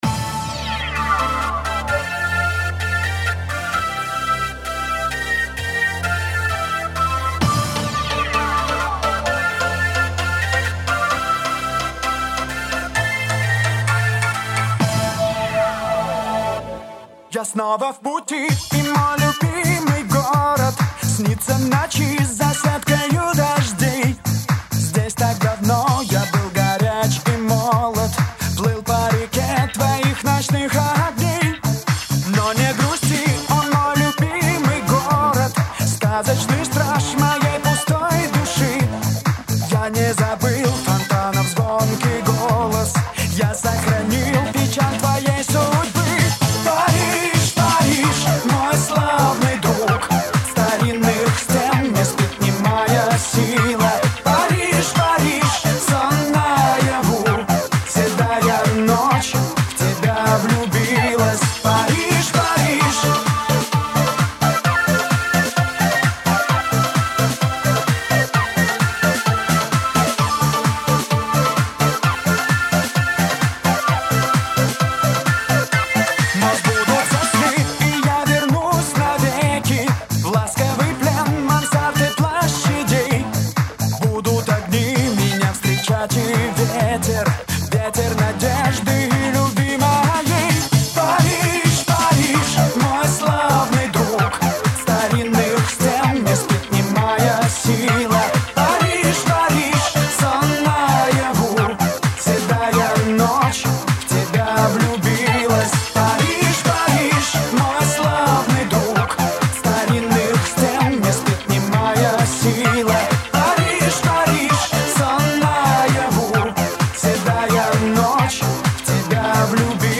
За качество извиняюсь, лучше не нашла